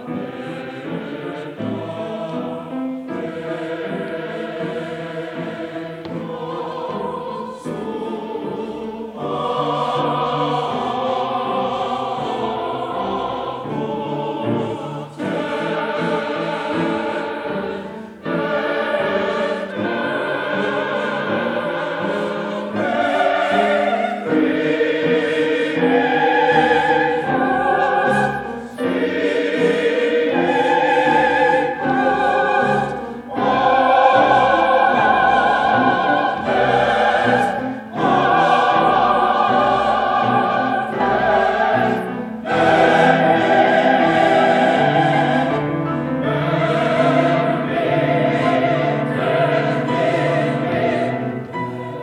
Highland Park, MI, High School Concert Choirs, 1954-1969
CD for the 1969 Spring Concert